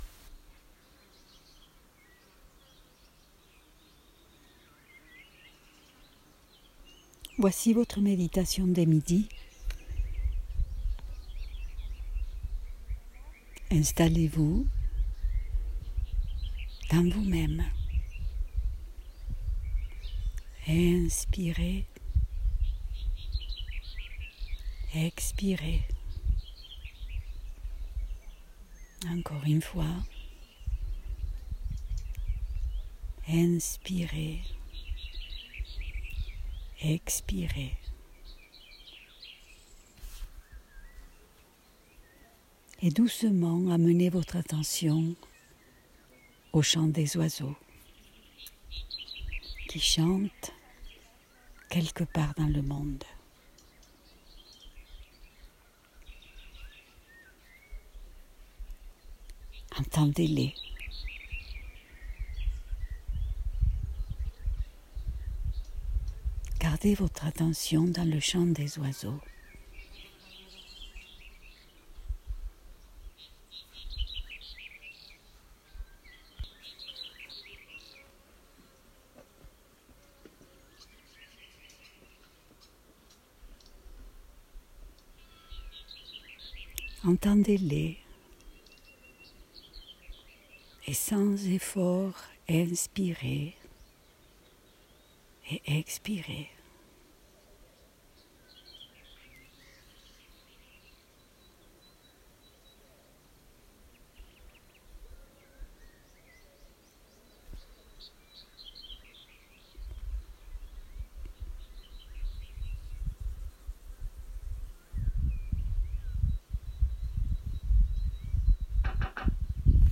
J’ai enregistré quelques méditations guidées pour mes patients; mais j’invite tous ceux qui le souhaitent à s’en servir.